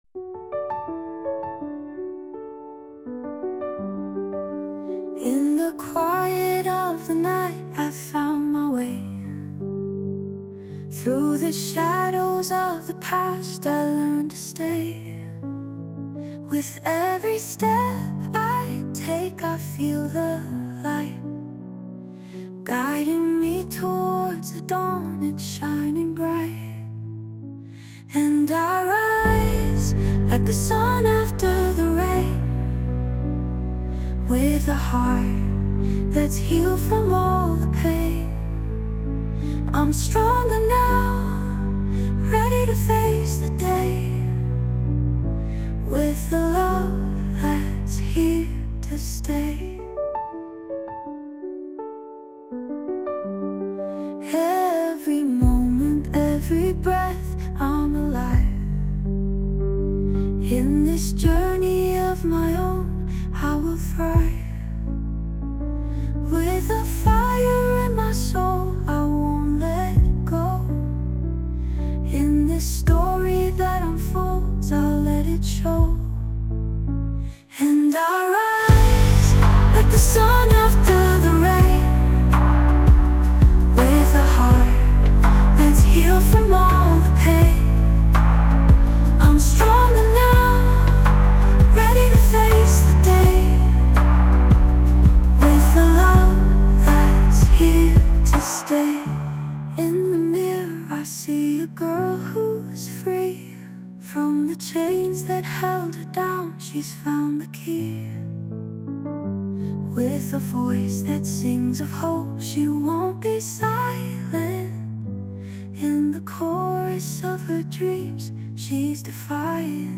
洋楽女性ボーカル著作権フリーBGM ボーカル
女性ボーカル洋楽 女性ボーカルプロフィールムービーエンドロール